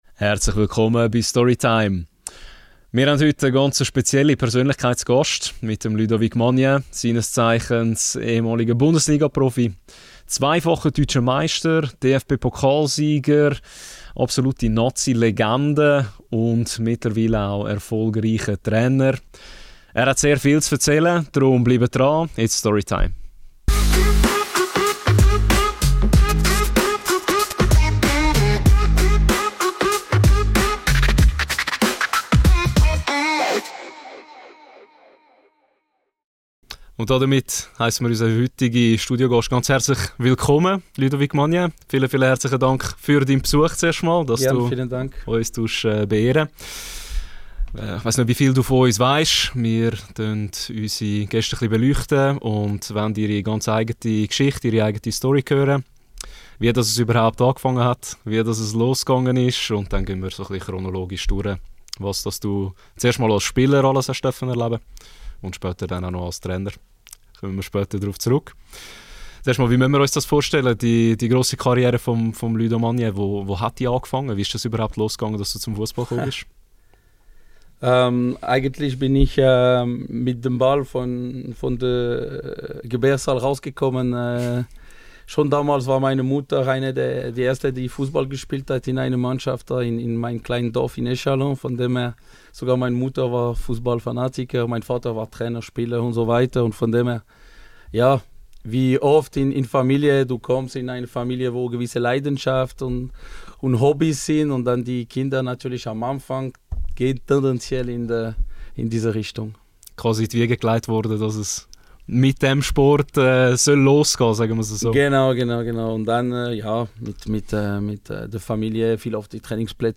Dazu teilt er emotionale Erinnerungen an legendäre Endrunden mit der Schweizer Nati , seinen Weg ins Trainerdasein – und was ihn bis heute im Fussball antreibt. Im Gespräch